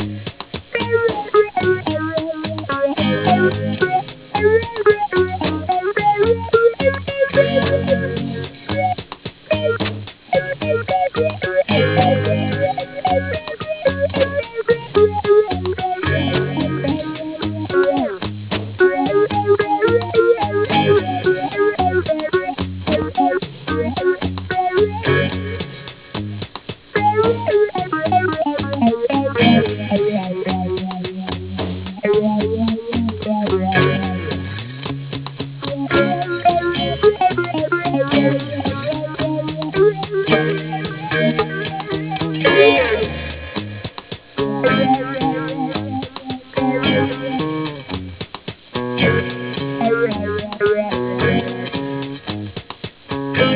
On this tune we were looking for a reggae feel.
We could have used a bass guitar that night.